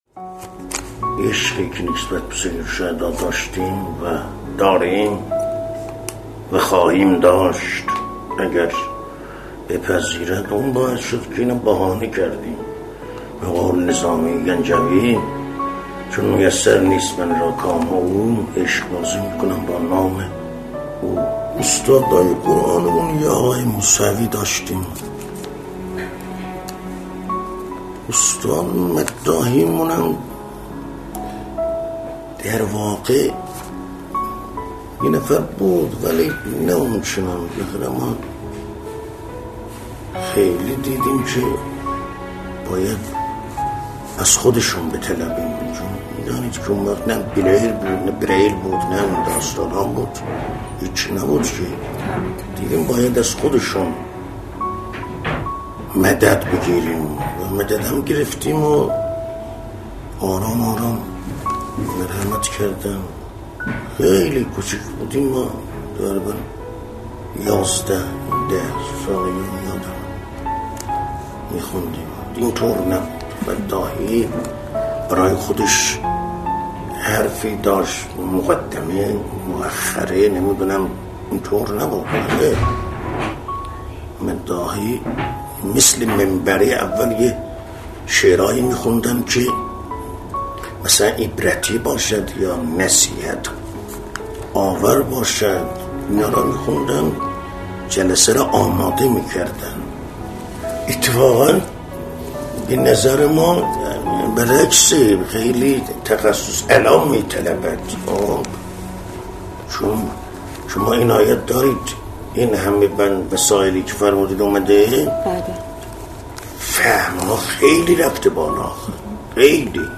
گروه اجتماعی: مداح پیشکسوت کشورمان، مردی که سال‌ها مرد میدان حسین(ع)، انقلاب و جنگ بود، از زندگی خود و خاطرات دیدار با امام خمینی(ره) و رهبر معظم انقلاب می‌گوید.